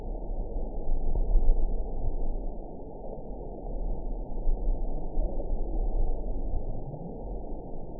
event 910602 date 01/22/22 time 22:27:59 GMT (3 years, 10 months ago) score 8.82 location TSS-AB04 detected by nrw target species NRW annotations +NRW Spectrogram: Frequency (kHz) vs. Time (s) audio not available .wav